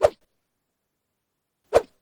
Razor cutting through air noises]
whiff.opus